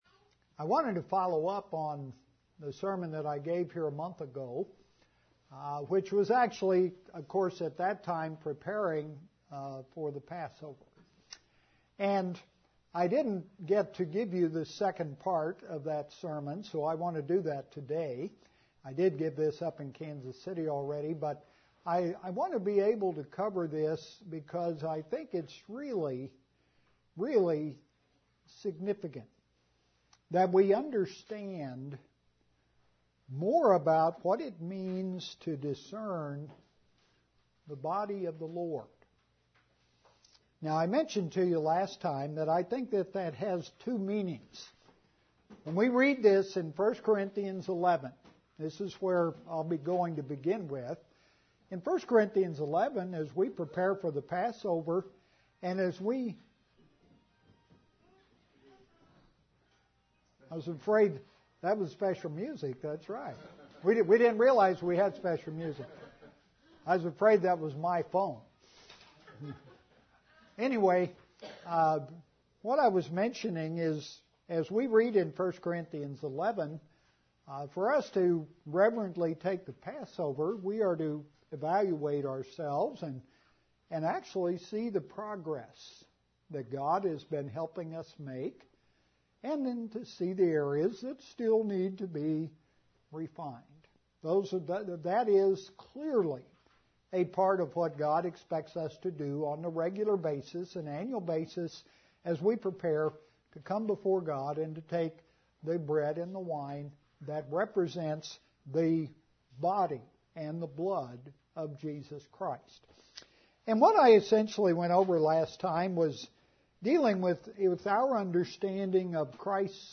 The second part of the sermon series on discerning the body of the Lord, in preparation for taking the Passover, and more broadly in our Christian lives.